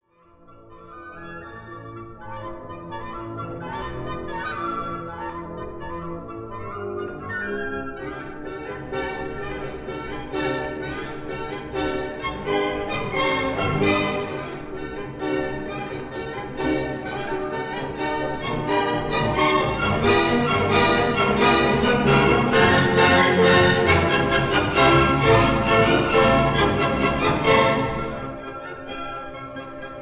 in C major
Orchestra
conductor
Recorded in the Kingsway Hall, London on 25 March 1953